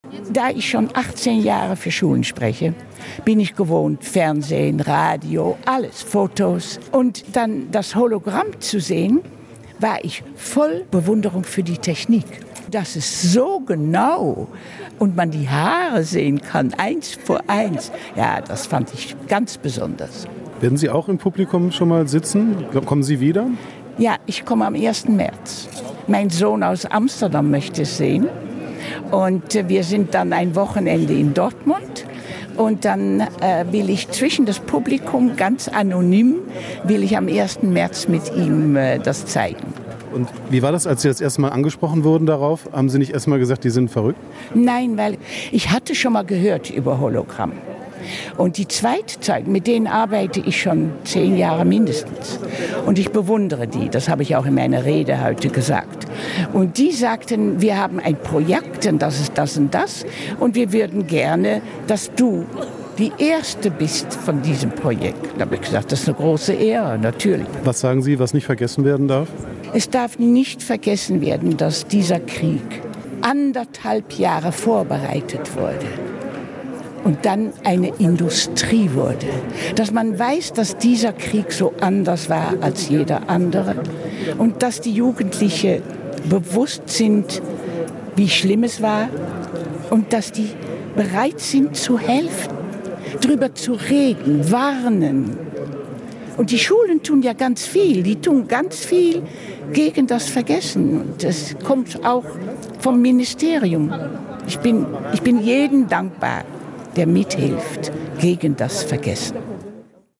Zeitzeugin